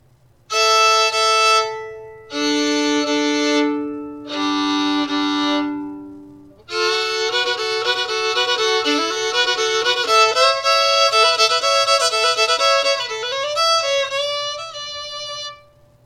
I would classify this one as loud in volume with moderately bright, even tone quality.